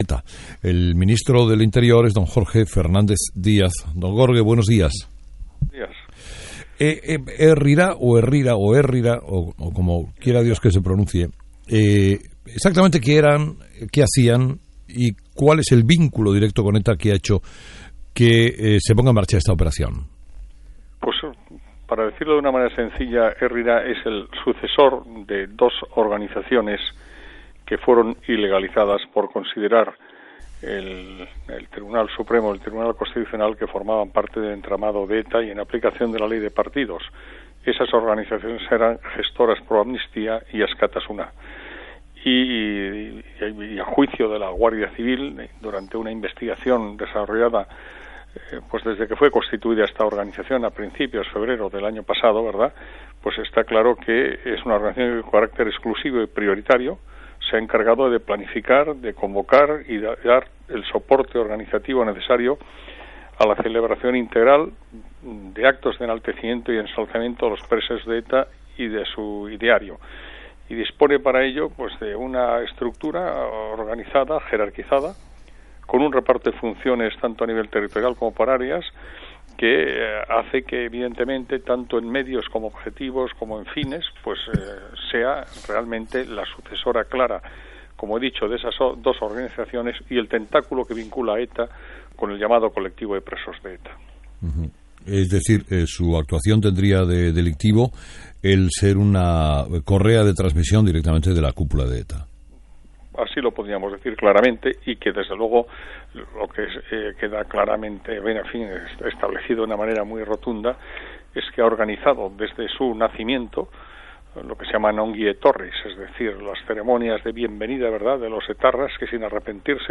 Entrevista a Jorge Fernández Díaz.
El ministro Jorge Fernández Díaz señala en una entrevista con Carlos Herrera que el Gobierno mantiene una postura firme con ETA y que “no van a ceder en ningún momento”, ya que considera que “después de dejar de actuar,  lo que tiene que hacer ETA es dejar de existir".